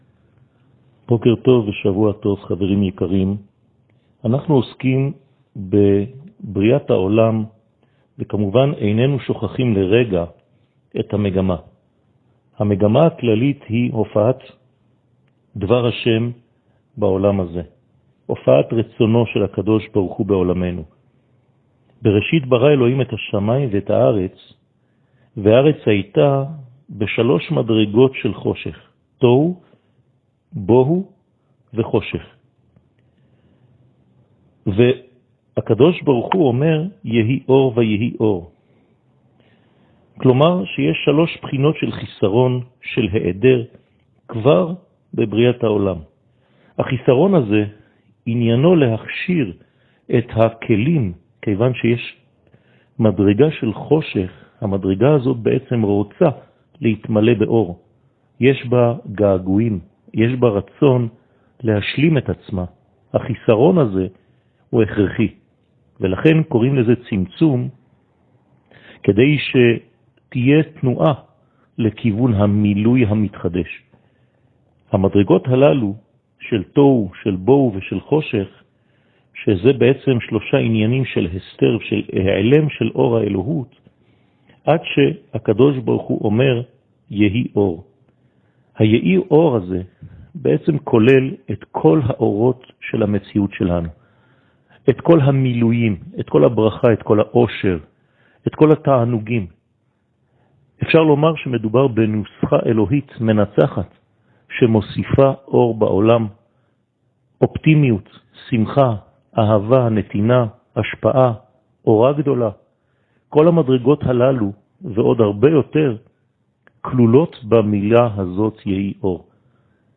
שיעור מ 03 אוקטובר 2021
שיעורים קצרים